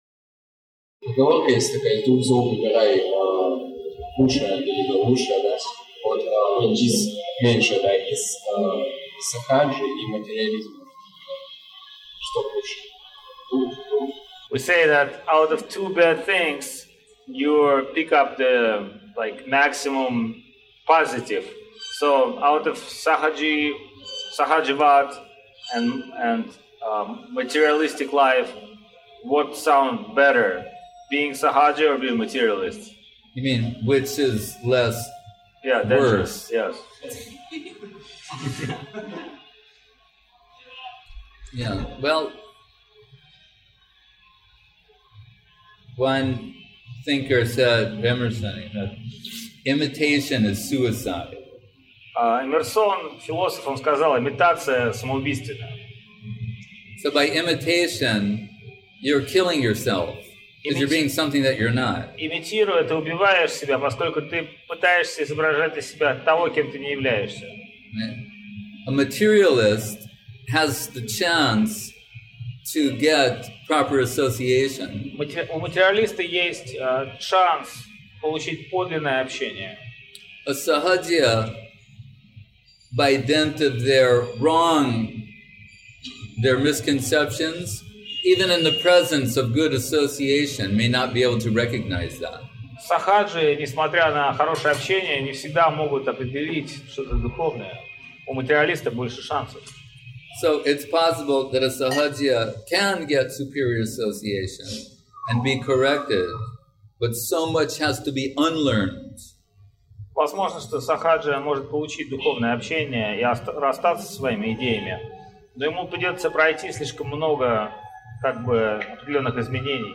Место: ШЧСМатх Навадвип